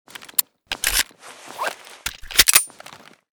usp_reload_empty.ogg